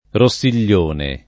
Rossiglione [ ro SS il’l’ 1 ne ]